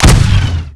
fire_bolt_med.wav